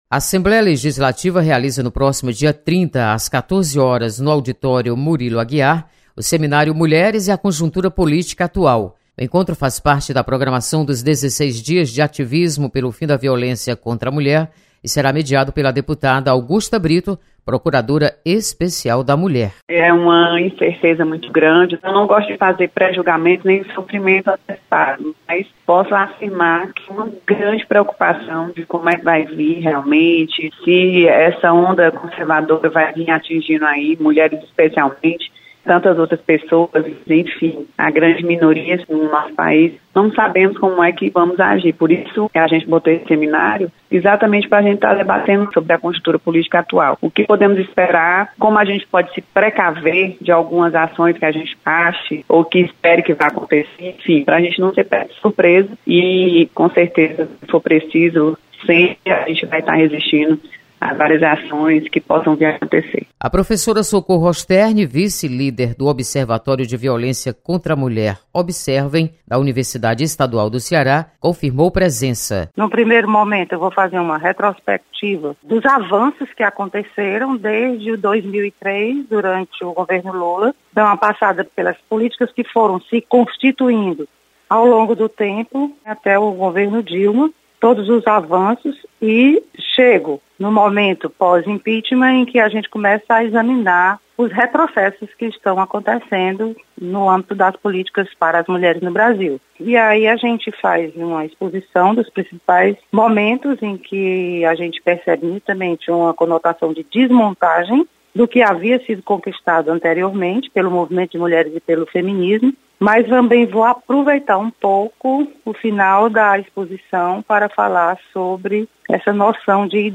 Seminário Mulheres e a Conjuntura Política Atual acontece dia 30 na Assembleia Legislativa. Repórter